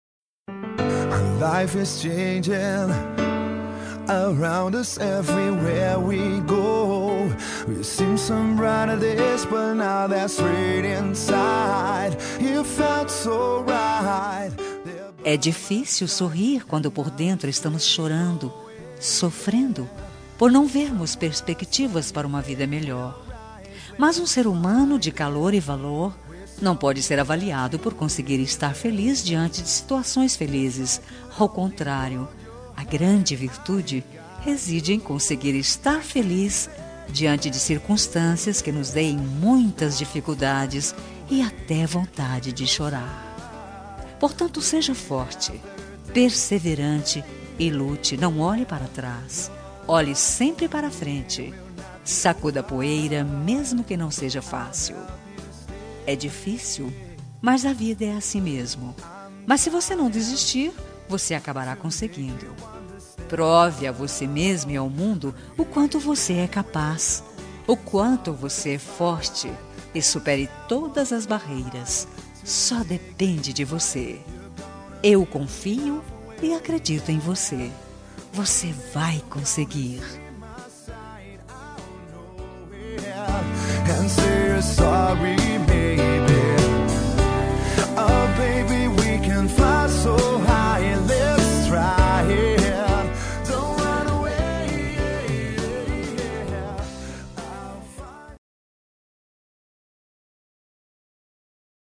Telemensagem de Otimismo – Voz Feminina – Cód: 8080 – Linda
8080-otimismo-fem.m4a